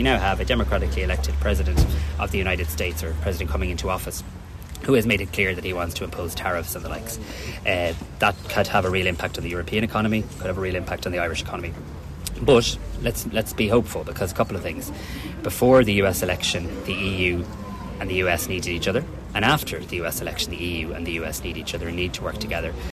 Taoiseach Simon Harris says he’ll be reminding Donald Trump the US also benefits from Irish companies operating over there.